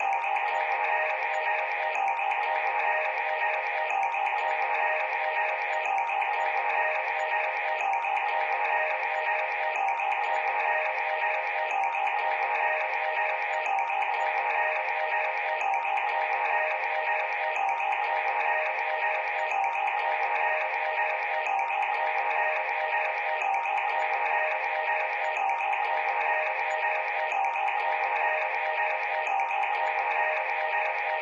环境气氛垫
描述：平滑的环境声音氛围循环。
Tag: 环境 无人机 大气